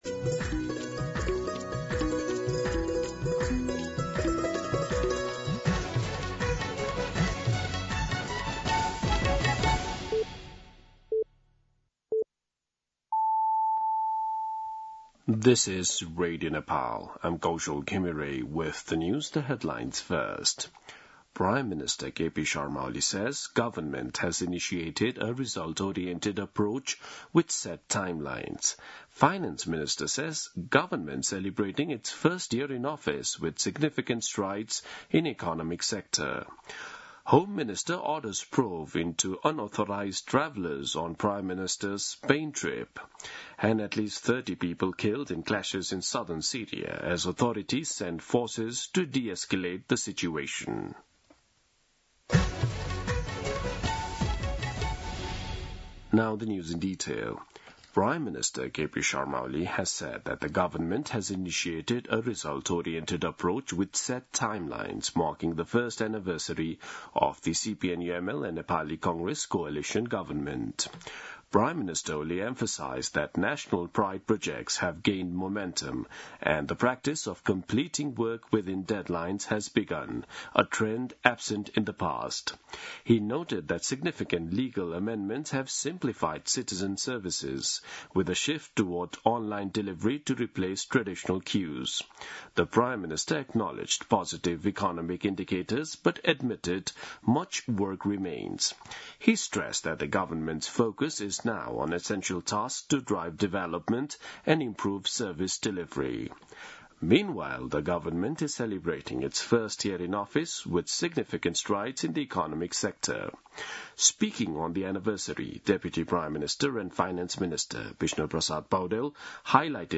दिउँसो २ बजेको अङ्ग्रेजी समाचार : ३० असार , २०८२
2-pm-English-News-3-30.mp3